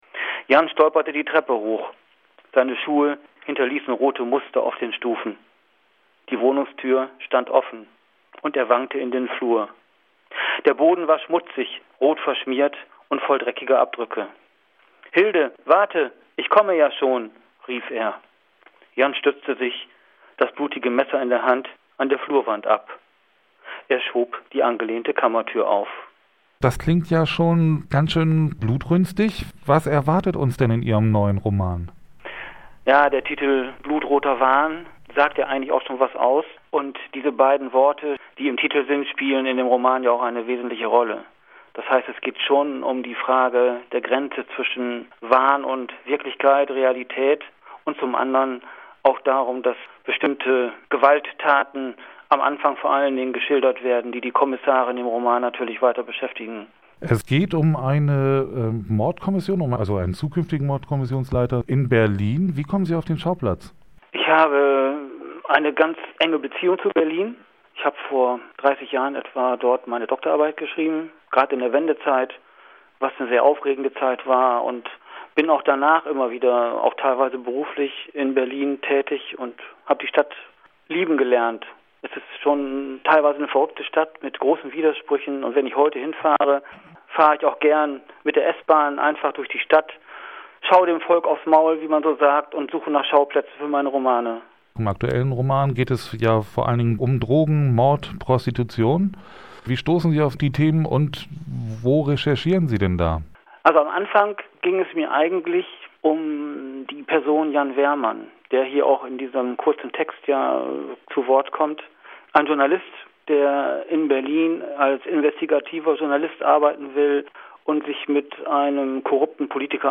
Interview Bürgerrundfunk zum Kriminalroman Blutroter Wahn Krimidinner im Cultimo November 2023 vor dem Auftritt gespannte Erwartung jetzt geht's los!